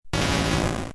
Cri de Sablaireau K.O. dans Pokémon Diamant et Perle.